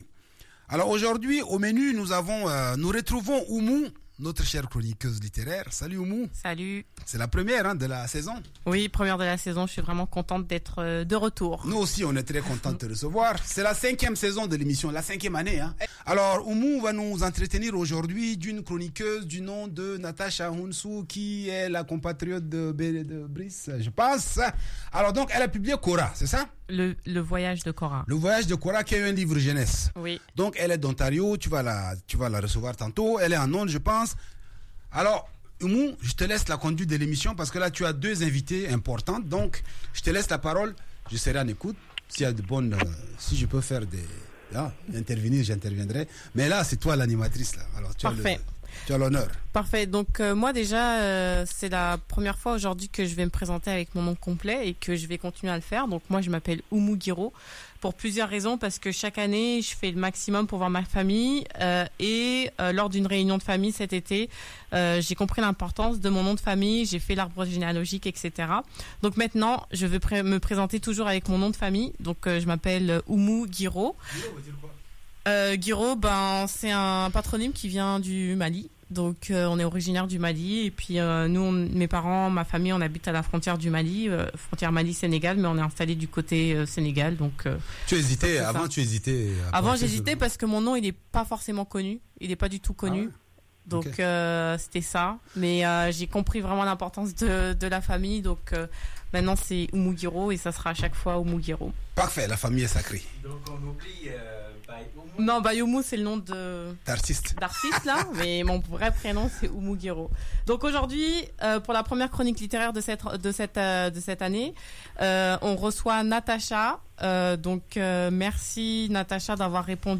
En septembre 2022, je suis revenue en studio!